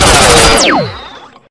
rifle2.ogg